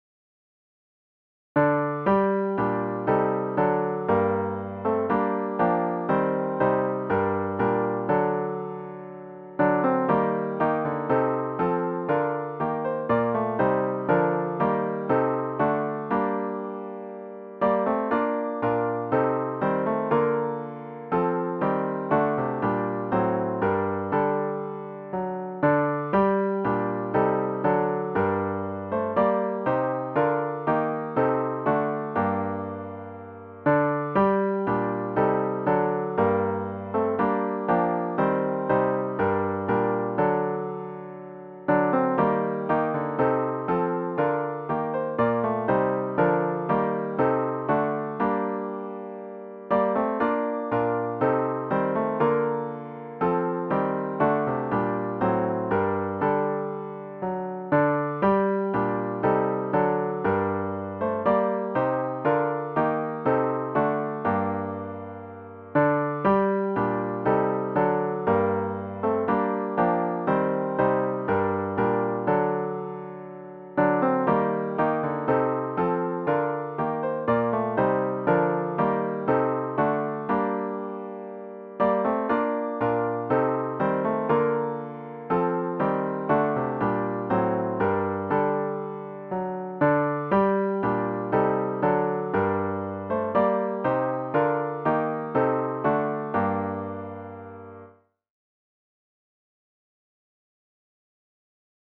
CLOSING HYMN   “Rejoice! Rejoice, Believers”   GtG 362
zz-362-Rejoice-Rejoice-Believers-3vs-piano-only.mp3